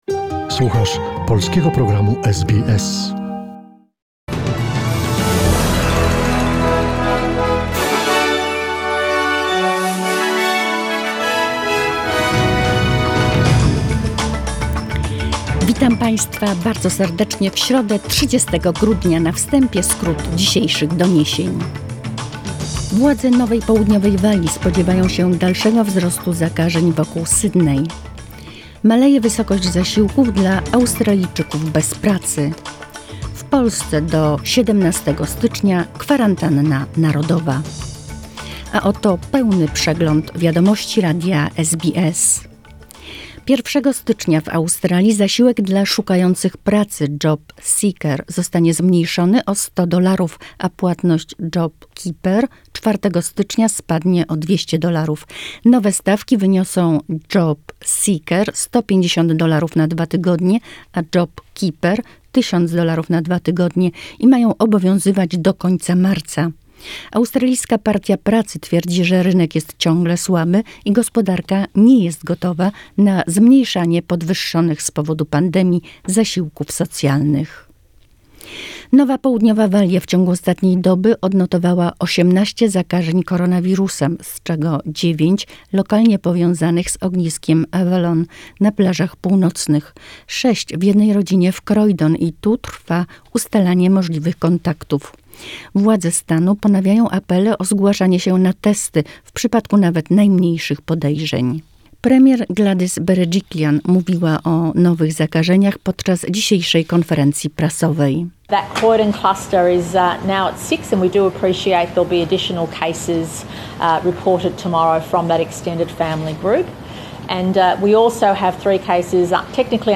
Wiadomości SBS, 30 grudzień 2020r.